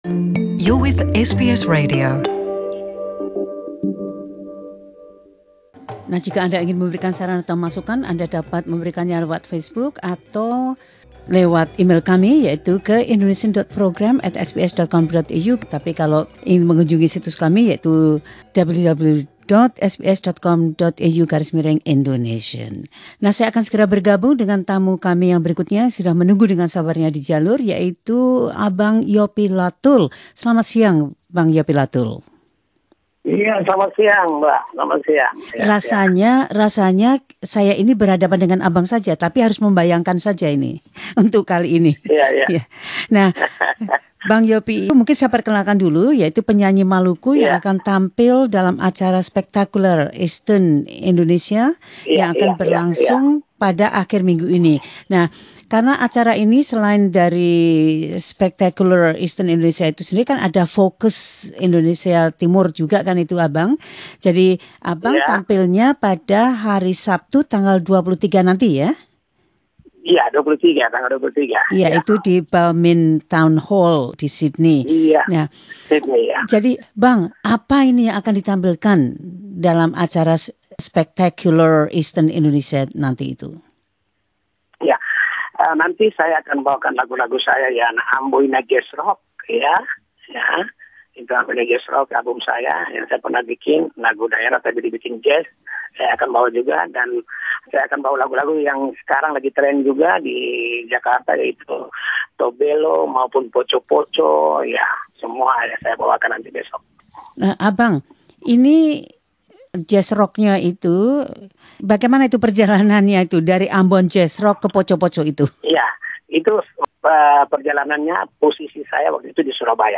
Duta musik Yopie Latul berbicara tentang keberhasilan kariernya yang panjang serta penampilannya yang akan datang di Spectacular Eastern Indonesia pada tanggal 23 Maret nanti.